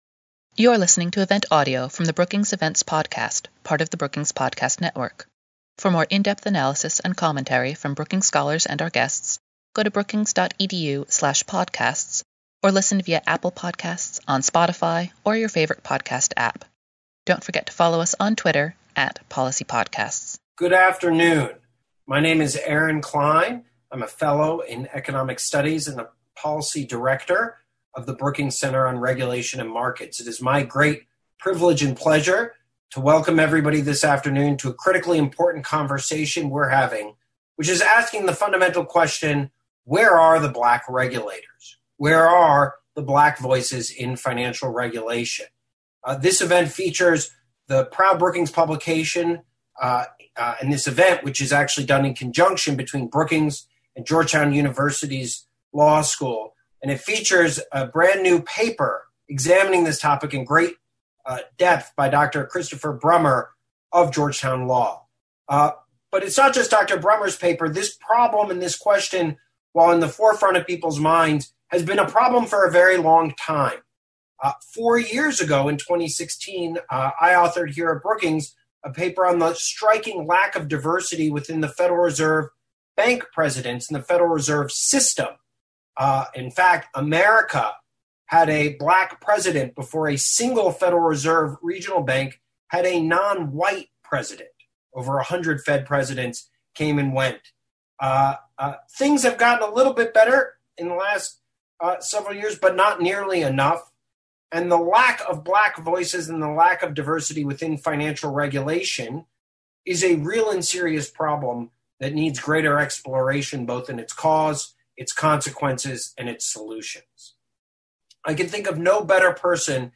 On September 2, Georgetown Law’s Institute of International Economic Law and the Brookings Institution hosted an event exploring the absence of African Americans in financial regulation and the drivers behind it.